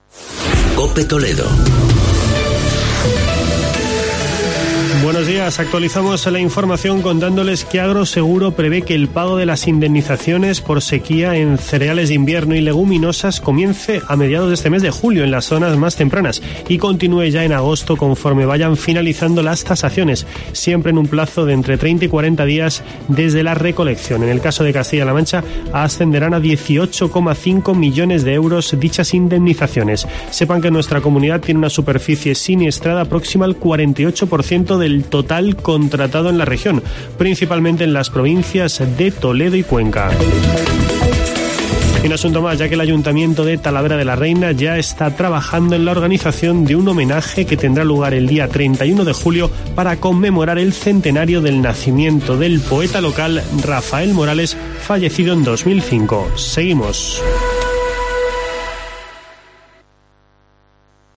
Boletín informativo de COPE Toledo.